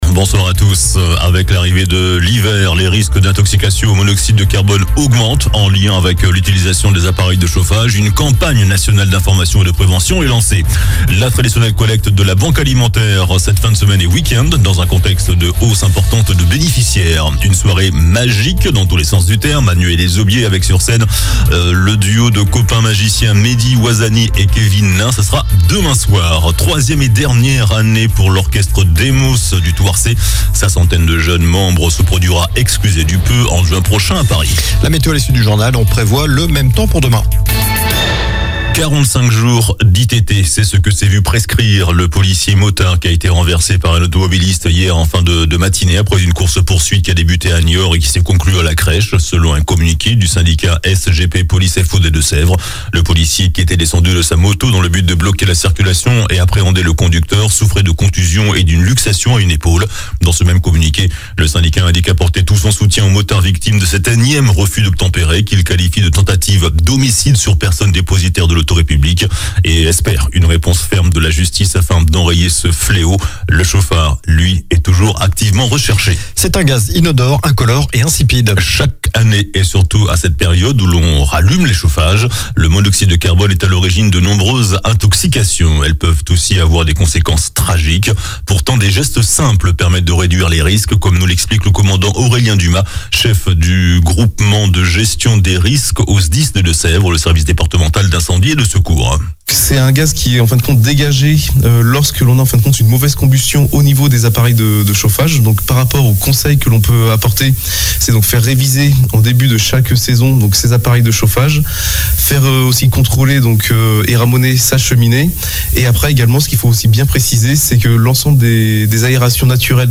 JOURNAL DU JEUDI 23 NOVEMBRE ( SOIR )